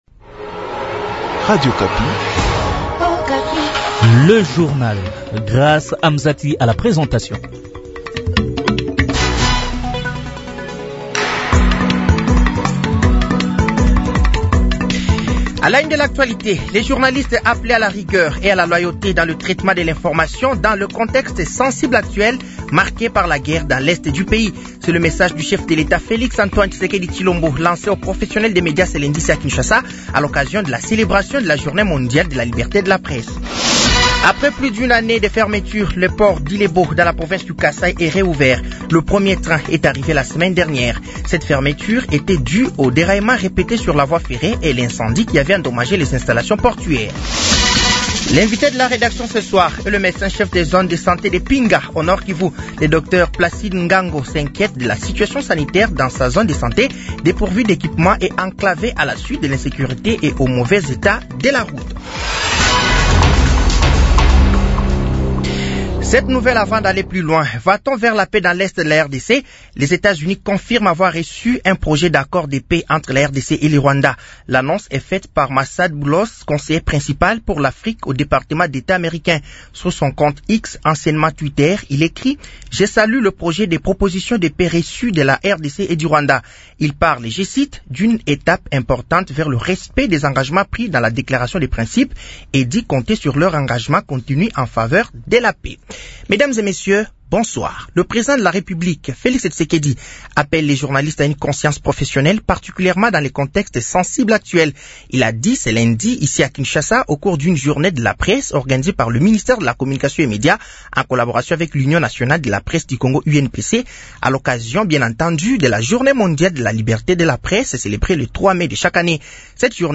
Journal français de 18h de ce lundi 05 mai 2025